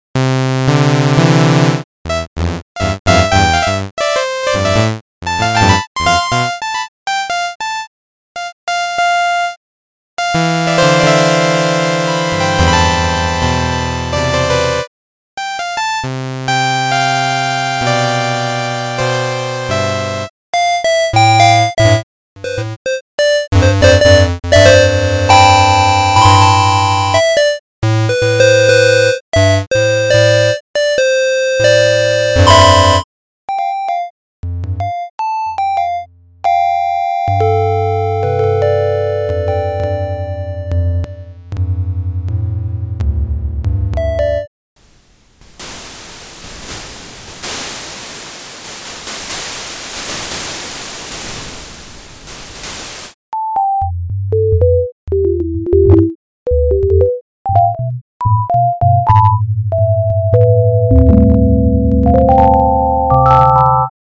A "fast" midi input to audio output synthesizer with 5 popular waveforms.
8. enjoy classic synthesizer sounds from the 80th !
Switch through the waveforms saw, square, triangle, noise and sinus: